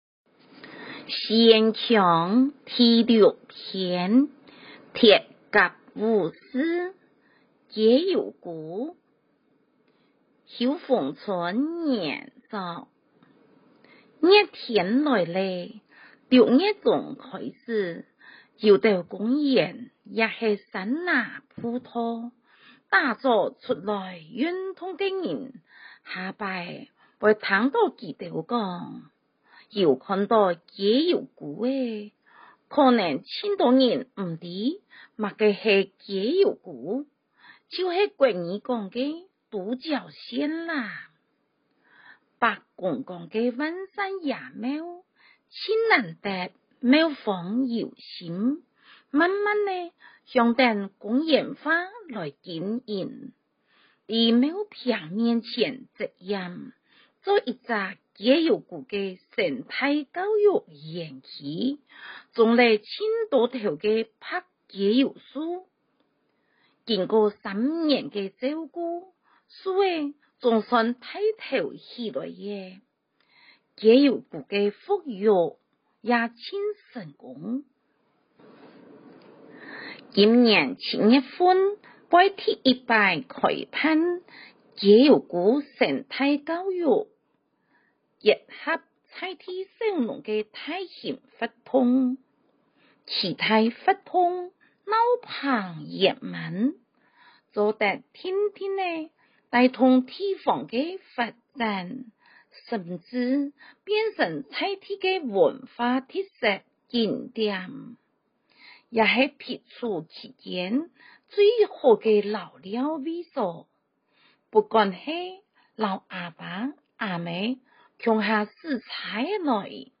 113學年度校慶語文競賽/客語朗讀題目和音檔